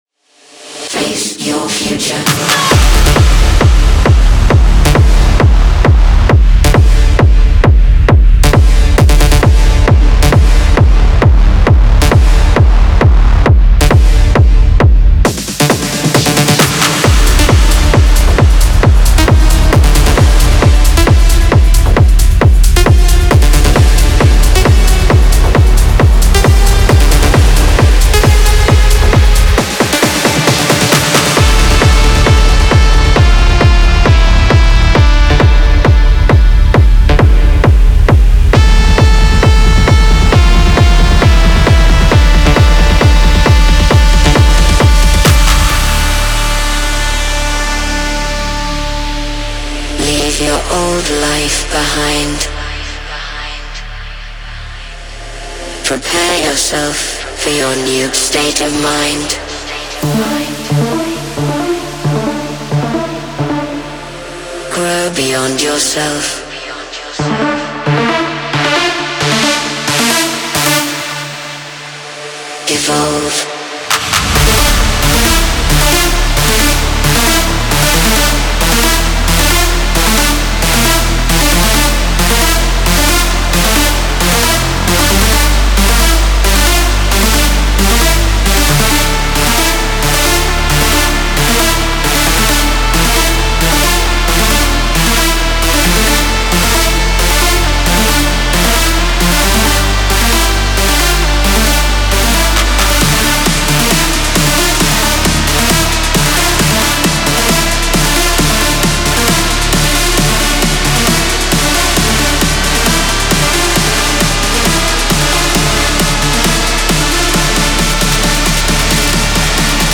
• Жанр: EDM